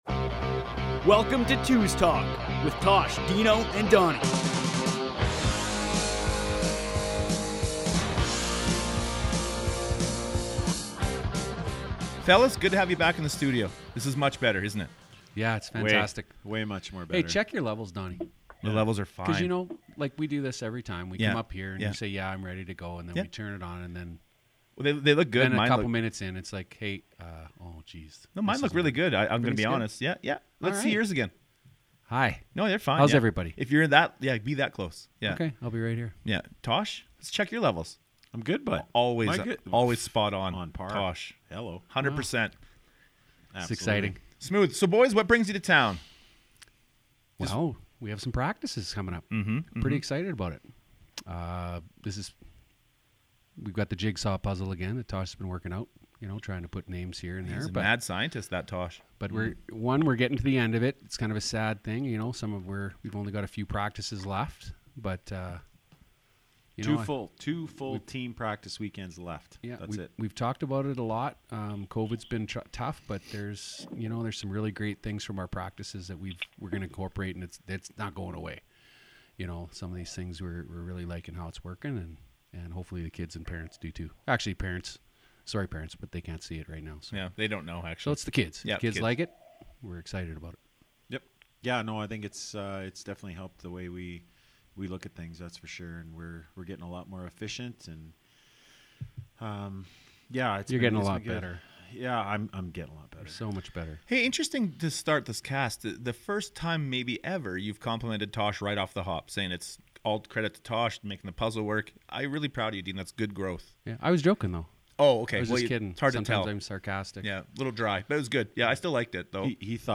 Lots of laughs and stories!